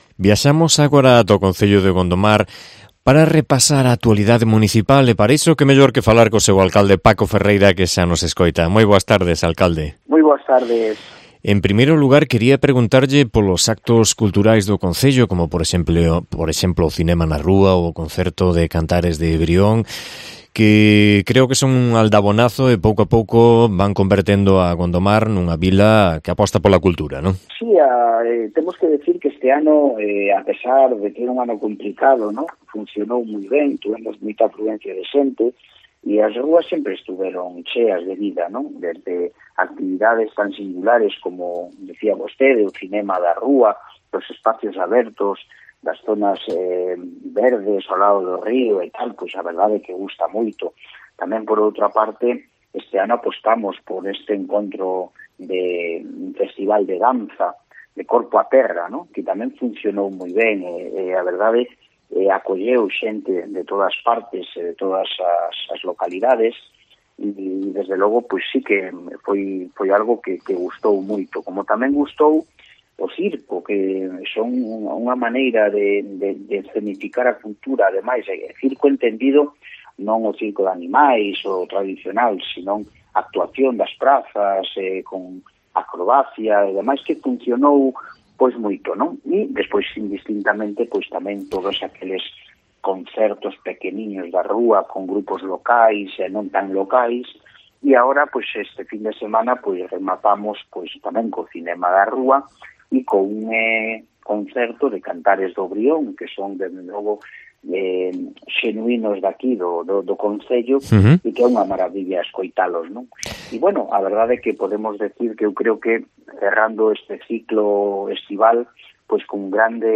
Entrevista a Paco Ferreira, alcalde de Gondomar